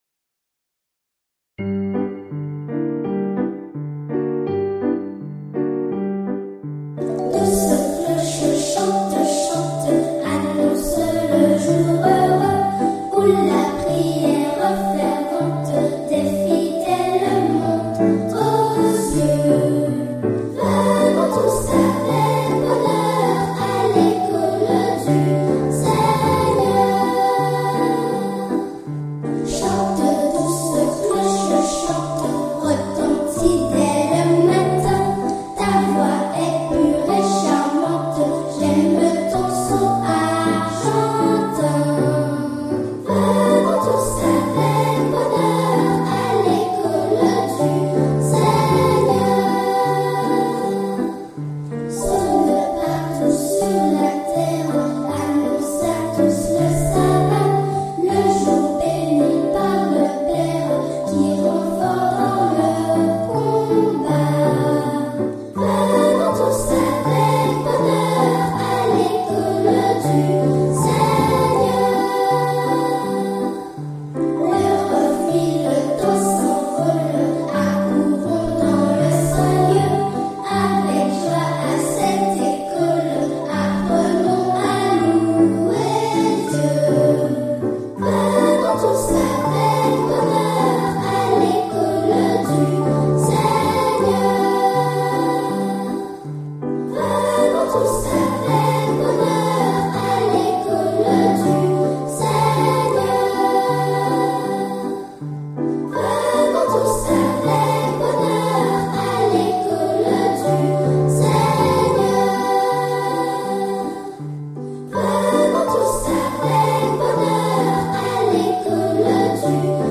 • Les enfants de Béguette et Raizet